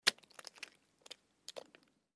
На этой странице собраны натуральные звуки приготовления яичницы: от разбивания скорлупы до аппетитного шипения на сковороде.
Звук разбитого яйца на раскаленной сковороде